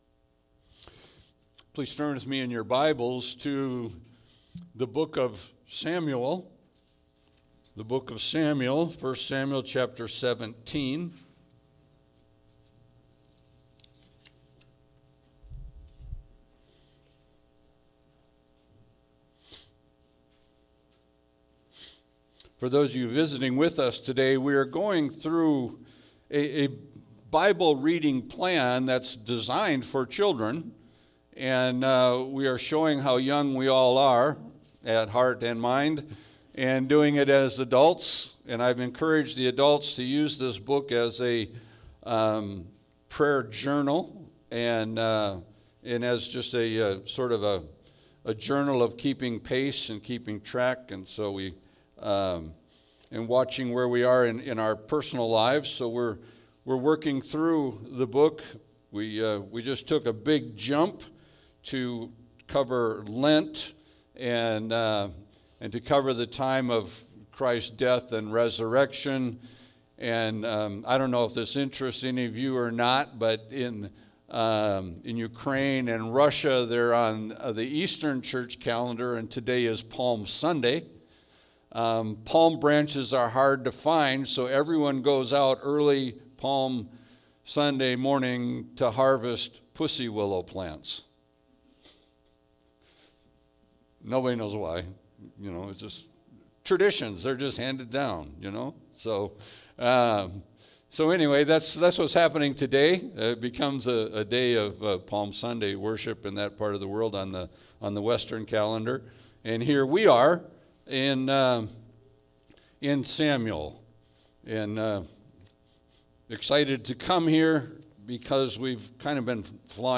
Passage: 1 Samuel 17 Service Type: Sunday Service